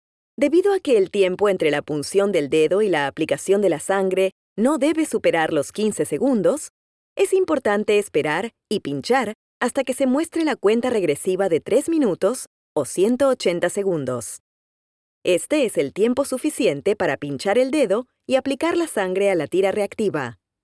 Female
Approachable, Character, Conversational, Corporate, Natural, Warm, Young
audiobook.mp3
Microphone: Manley reference Cardioid